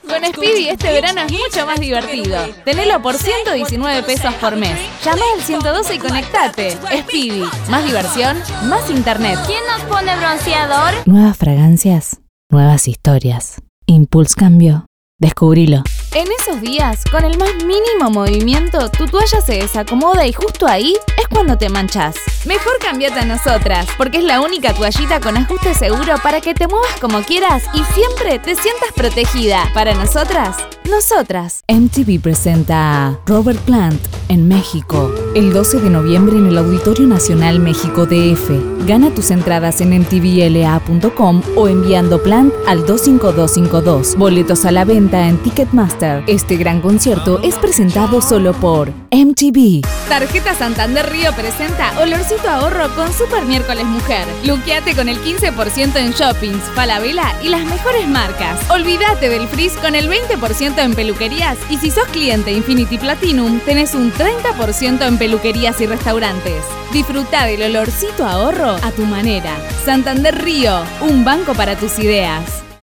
Voz dúctil, alegre, cálida, amable y fresca.
Sprechprobe: Industrie (Muttersprache):
Ductile voice, cheerful, warm, friendly and fresh.